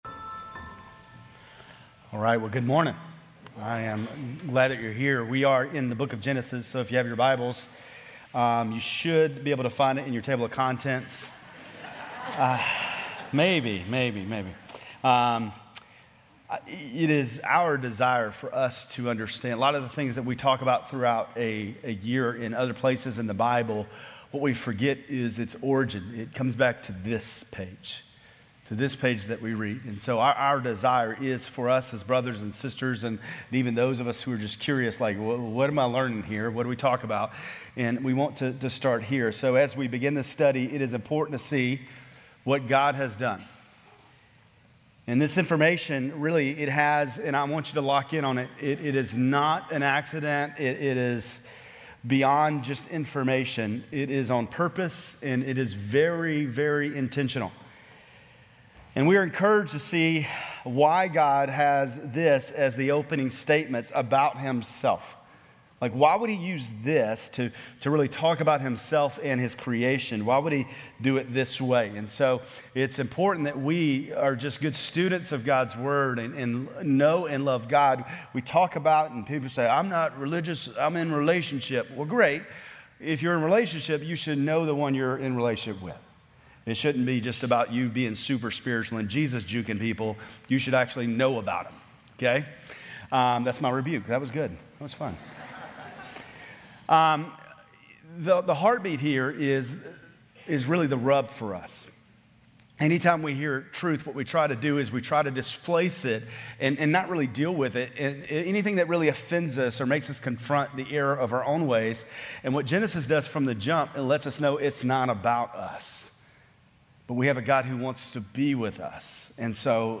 Grace Community Church Lindale Campus Sermons Genesis - Creator Aug 18 2024 | 00:29:56 1x 00:00 / 00:29:56 Subscribe Share RSS Feed Share Link Embed